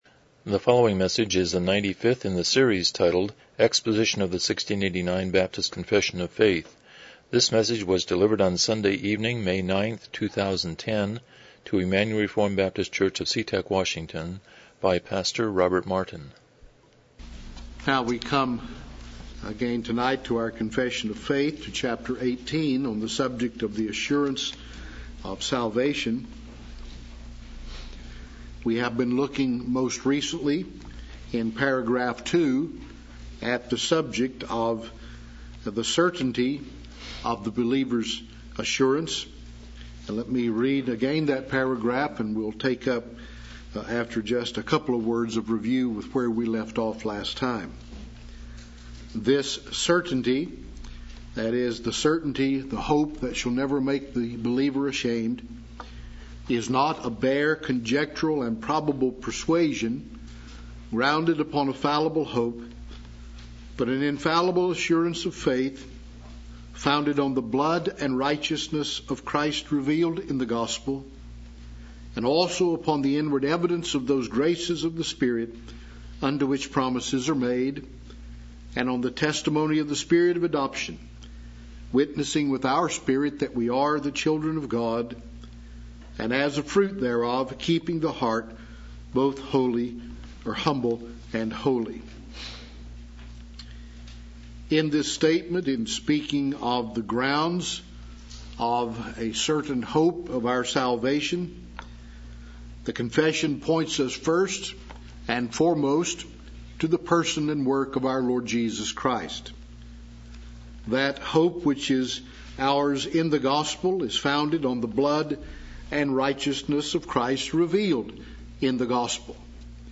1689 Confession of Faith Service Type: Evening Worship « The Value of Christ